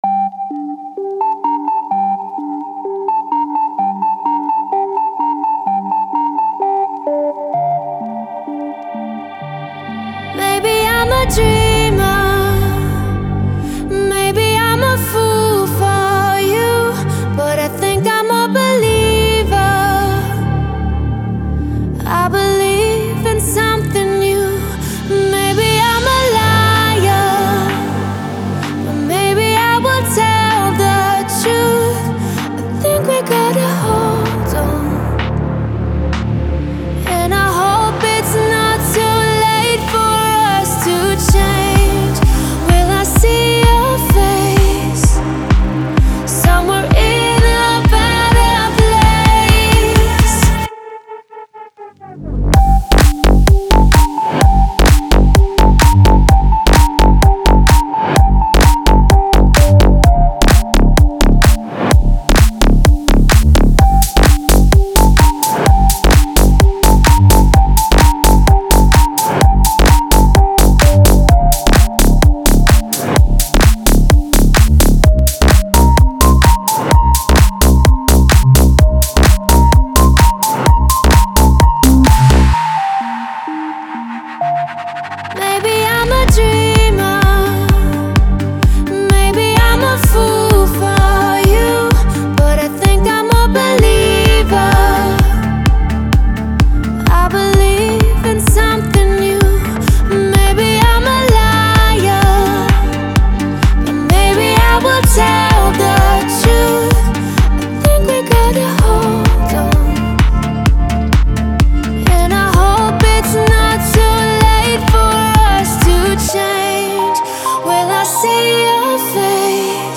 это завораживающая электронная композиция